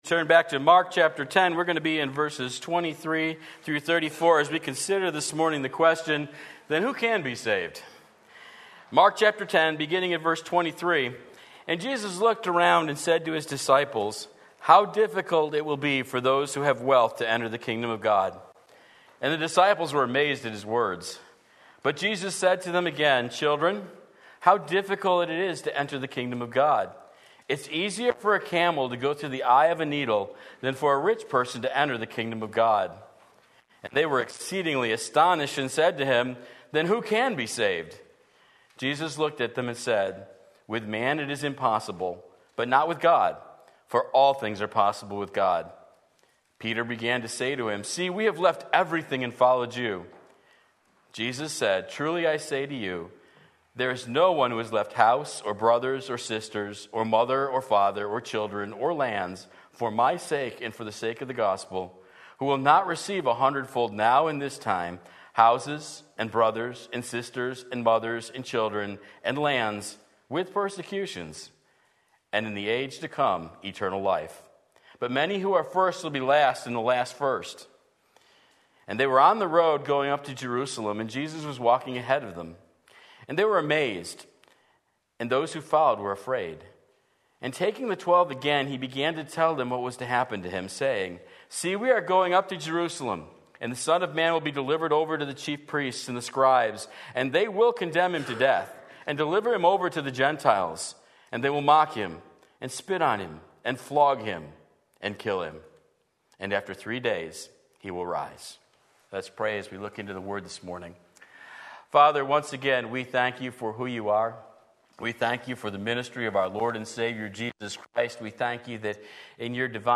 Sermon Link
Mark 10:23-34 Sunday Morning Service